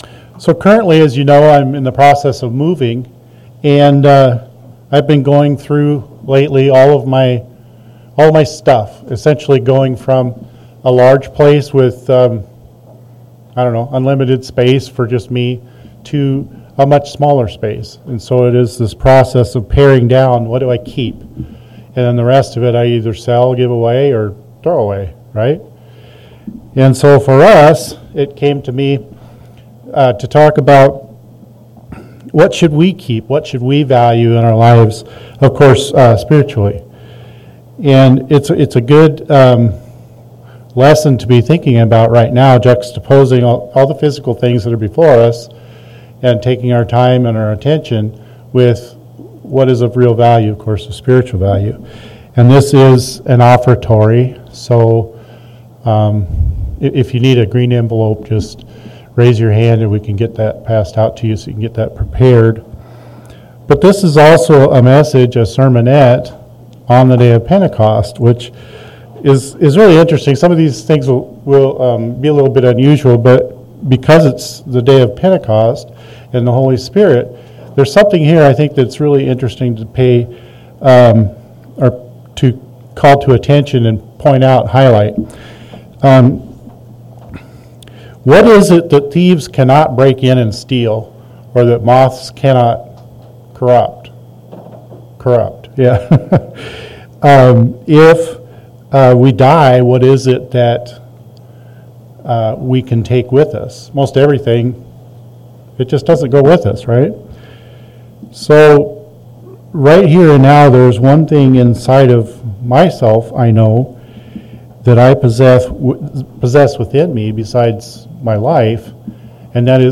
Sermons
Given in Omaha, NE